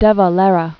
(dĕvə-lĕrə, -lîrə), Eamon 1882-1975.